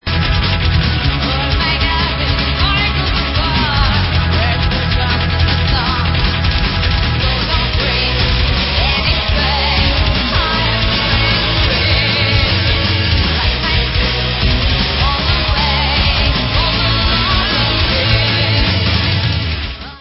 CZECH FEMALE FRONTED POWER METAL BAND